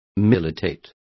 Complete with pronunciation of the translation of militating.